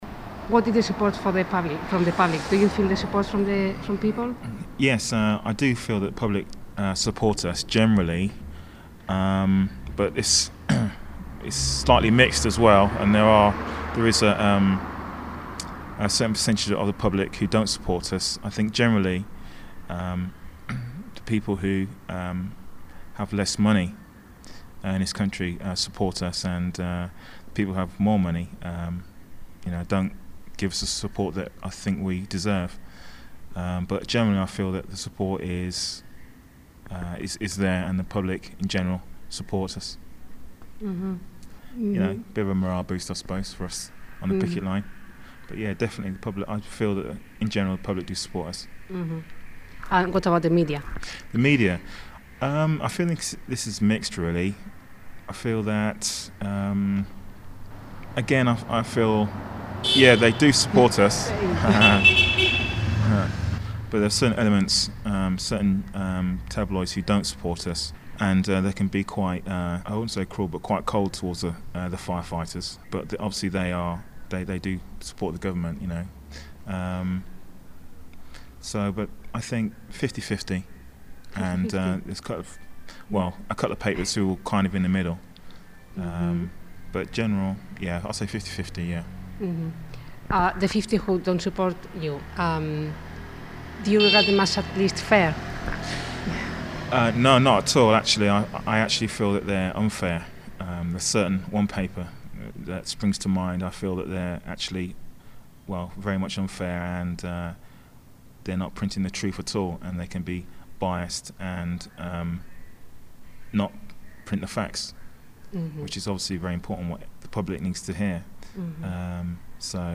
fourth interview.